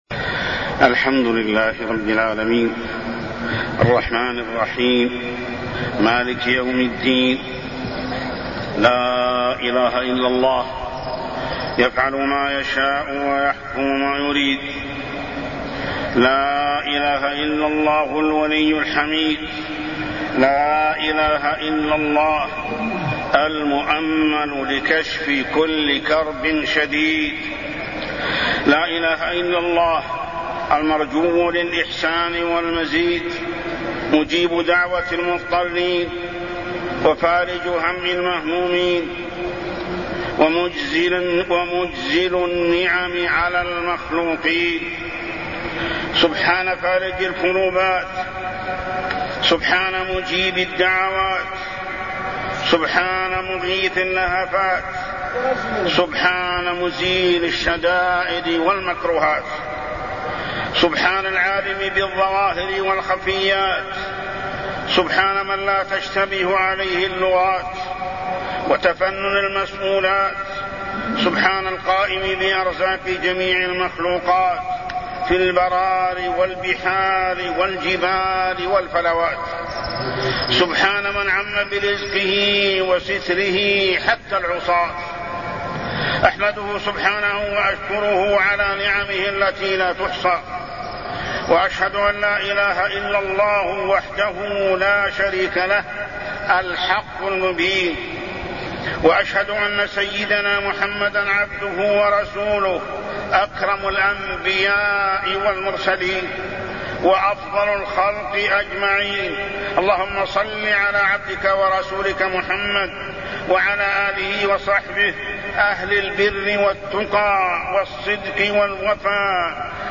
تاريخ النشر ١٤ ذو القعدة ١٤٢٠ هـ المكان: المسجد الحرام الشيخ: محمد بن عبد الله السبيل محمد بن عبد الله السبيل الإستغفار والتوبة